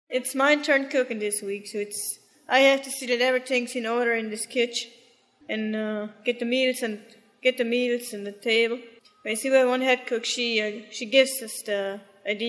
Hutterites-women-20s-monologue-EJDD.mp3